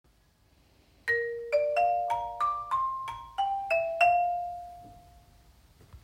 Xylofoni
Xylofoni.m4a